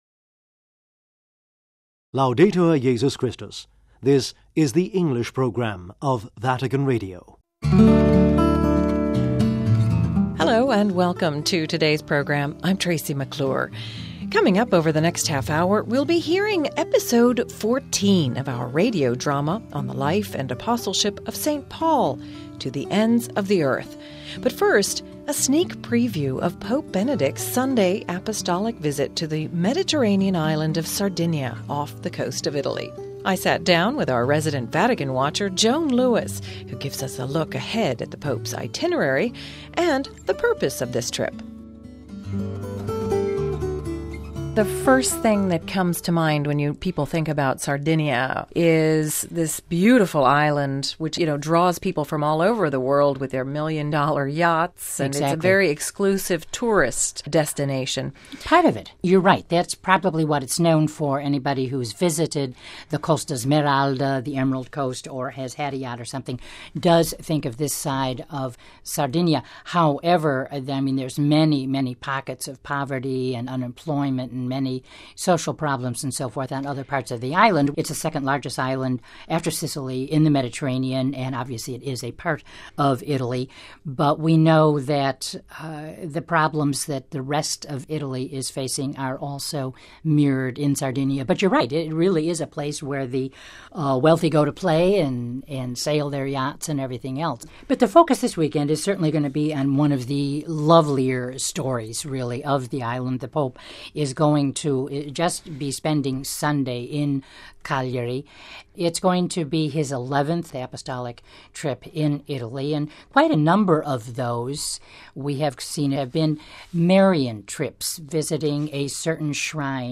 radio drama